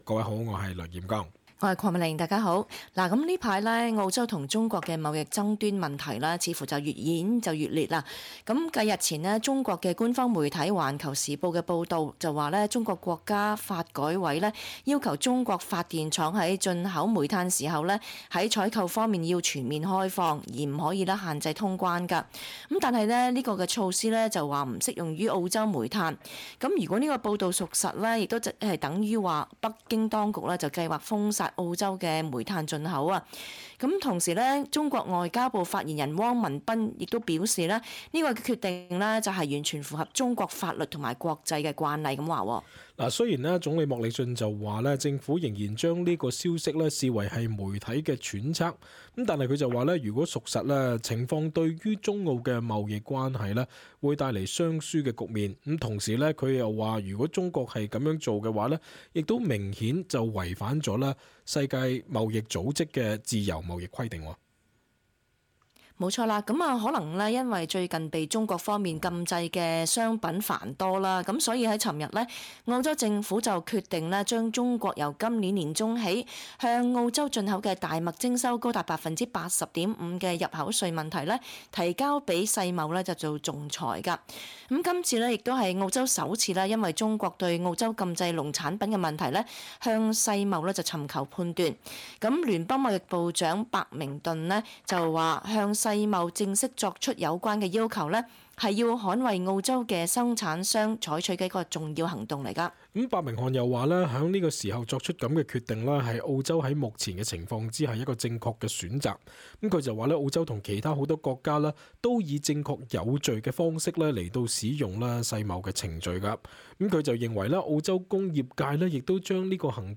cantonese_-_talkback_final_dec_17.mp3